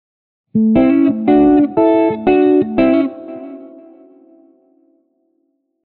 HYBRID PICKING STUDIAMO QUALCHE LICK